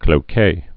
(klō-kā)